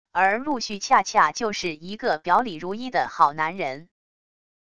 而陆续恰恰就是一个表里如一的好男人wav音频生成系统WAV Audio Player